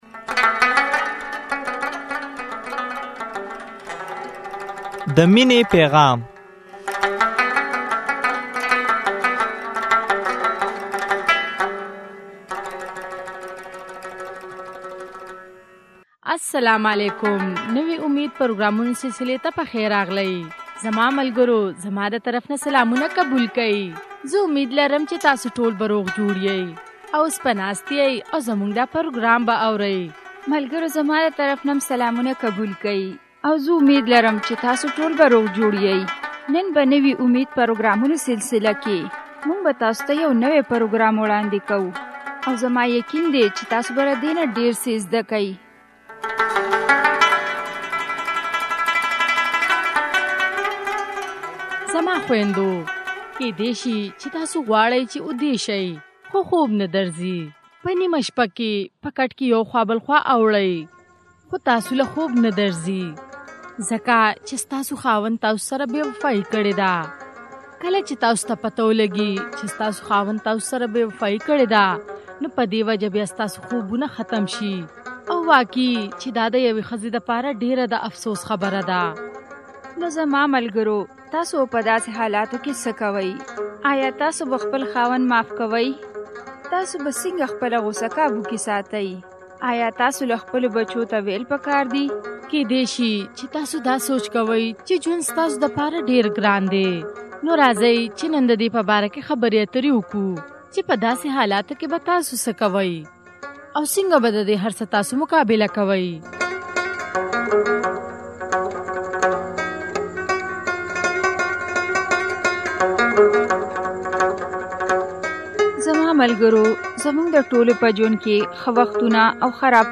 يوه ښځه د خپل خاوند باره کښې خبره کوى چې هغۀ د بلې ښځې دپاره خپل د وادۀ لس کالو ته ونۀ کتل او هغې له يې دوکه ورکړه او پرې يې ښوده. د چا نه چې طلاق غوښتے کيږى د هغه کس دپاره طلاق ډېر درد ناک او د تکليف سبب وى.